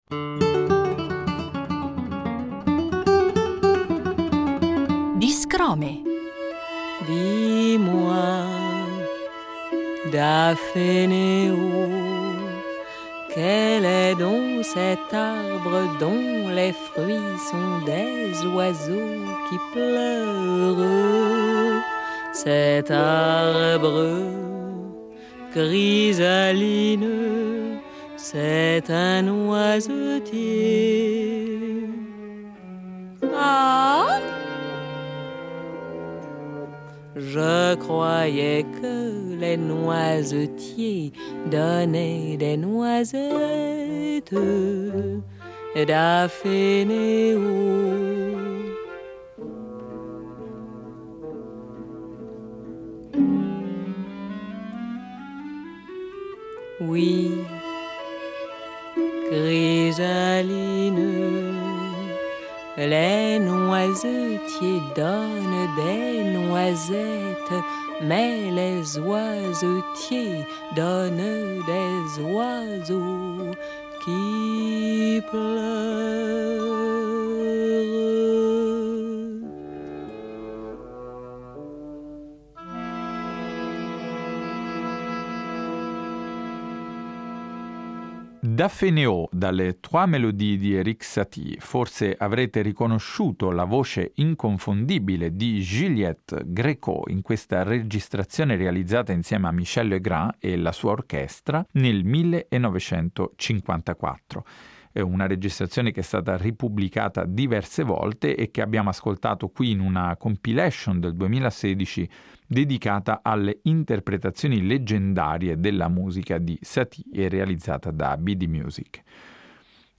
un itinerario sonoro che attraversa l’intera sua produzione pianistica, sinfonica e cameristica mettendo in rilievo tra le altre cose i suoi rapporti con altri compositori